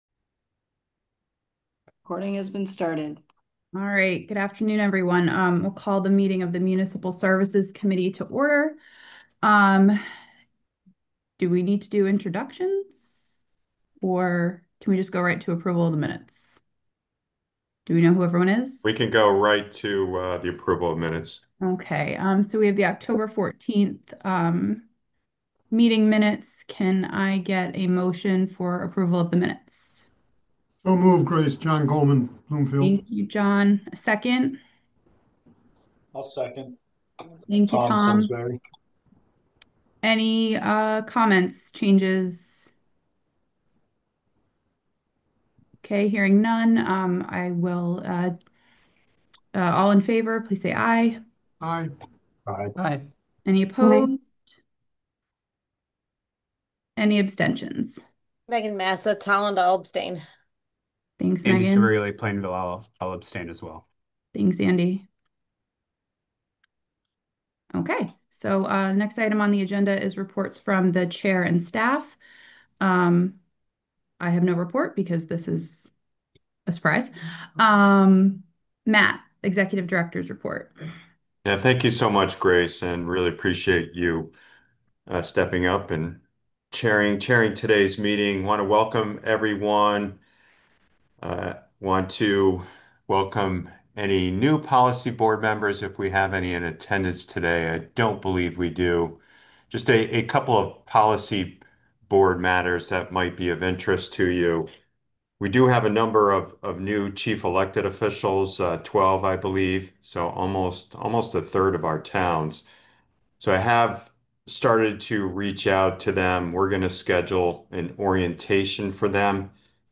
*Meeting access is remote only, consistent with provisions specified in Connecticut Public Act 22-3. This meeting will be recorded.